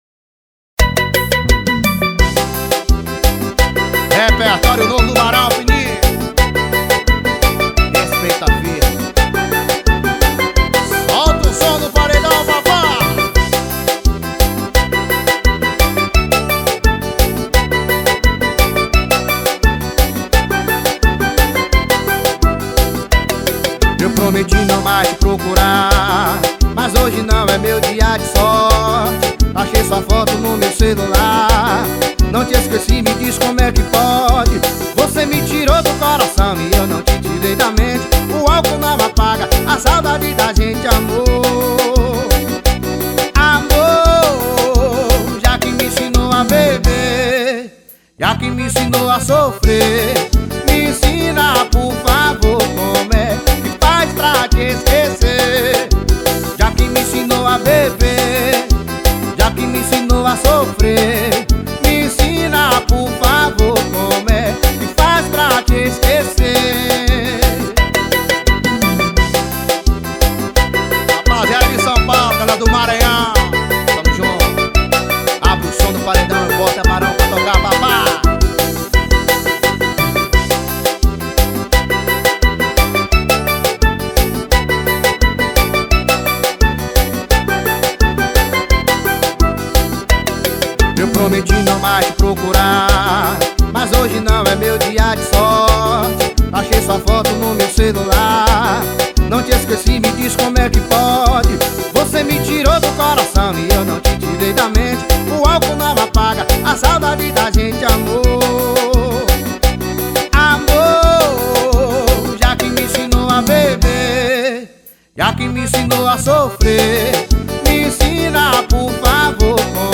2024-02-16 22:15:00 Gênero: Forró Views